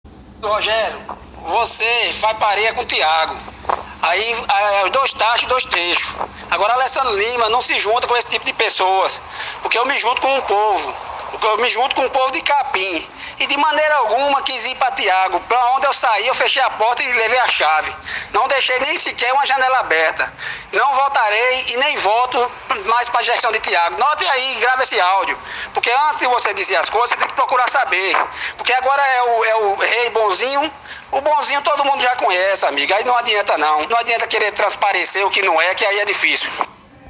Ouça o áudio do Vereador Alessandro Lima: